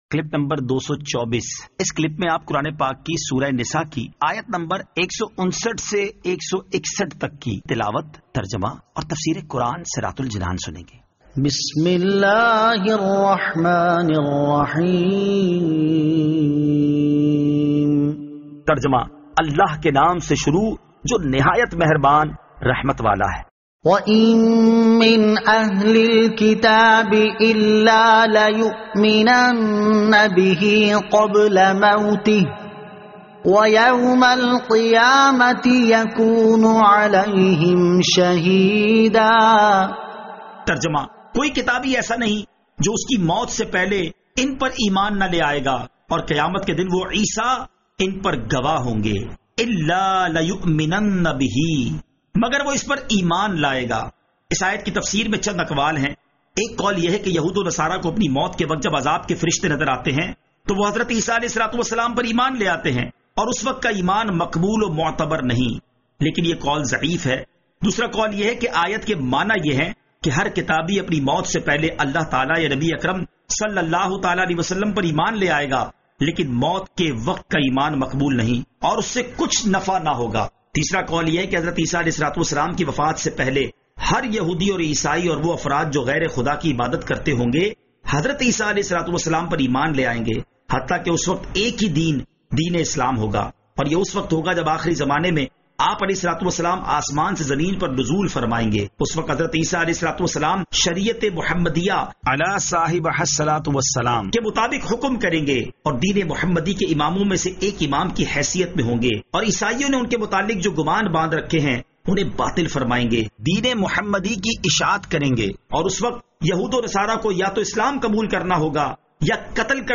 Surah An-Nisa Ayat 159 To 161 Tilawat , Tarjama , Tafseer
2020 MP3 MP4 MP4 Share سُوَّرۃُ النِّسَاء آیت 159 تا 161 تلاوت ، ترجمہ ، تفسیر ۔